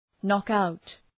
Προφορά
{‘nɒk,aʋt}